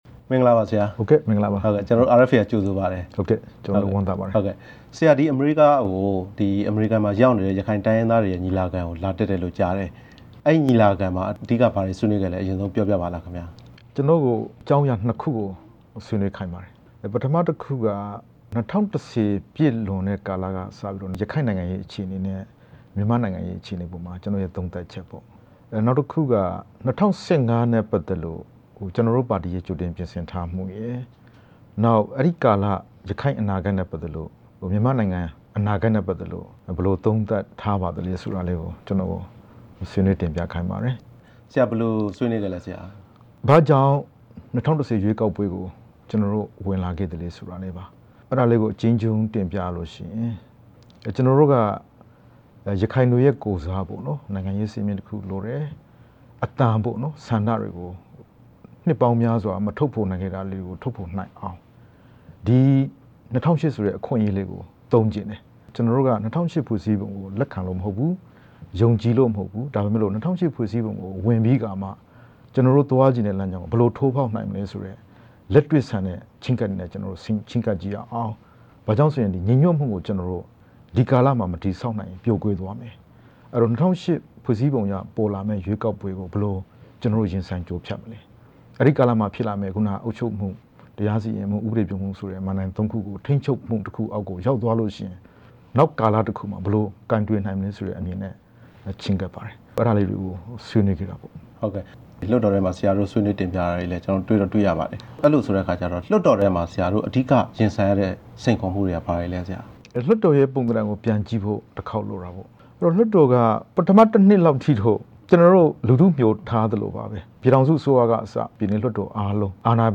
ဒေါက်တာ အေးမောင်နှင့် RFA ရုံးချုပ်မှာ တွေ့ဆုံမေးမြန်းချက်
အမေရိကန်ပြည်ထောင်စု တန်နက်ဆီပြည်နယ်မှာ စက်တင်ဘာလ ၁ ရက်နဲ့ ၂ ရက်နေ့က ကျင်းပခဲ့တဲ့ ရခိုင်တိုင်းရင်းသားတွေရဲ့ ညီလာခံကို တက်ရောက်ခဲ့တဲ့ ရခိုင်တိုင်းရင်းသားများ တိုးတက်ရေးပါတီ RNDP ဥက္ကဌ အမျိုးသားလွှတ်တော်ကိုယ်စားလှယ် ဒေါက်တာအေးမောင်ကို ဝါရှင်တန်ဒီစီြမို့တော်က RFA ရုံးချုပ်မှာ တွေ့ဆုံမေးမြန်းခွင့်ရခဲ့ပါတယ်။